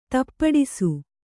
♪ tappaḍisu